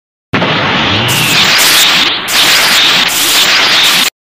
Play, download and share dbz powering up ssj2 original sound button!!!!
dbz-powering-up-ssj2.mp3